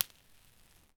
sparkle-3dio5bmo.wav